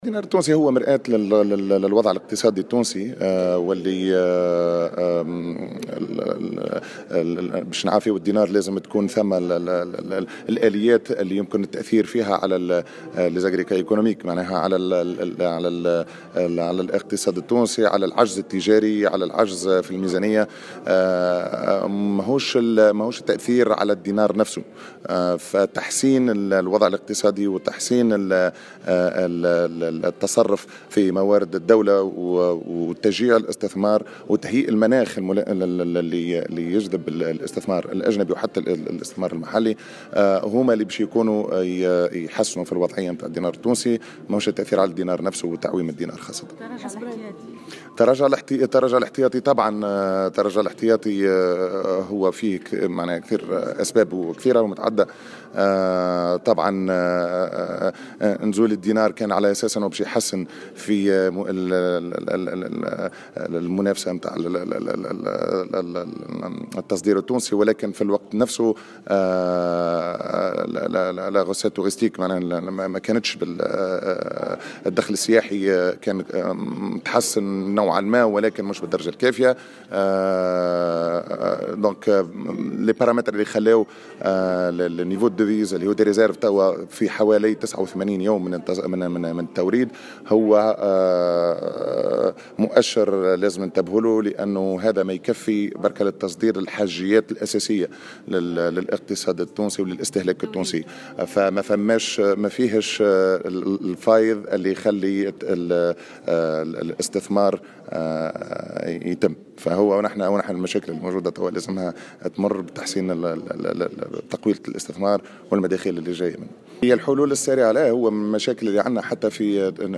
وأضاف في تصريح لـ "الجوهرة اف أم"، على هامش ملتقى نظمه المعهد التونسي للدراسات الاستراتيجية، اليوم الأربعاء في تونس العاصمة حول الدينار التونسي، أن تحسين الوضع الاقتصادي وحسن التصرف في موارد الدولة وخلق مناخ ملائم للاستثمار كلها عوامل ستساهم في تحسين أداء الدينار وليس تعويمه.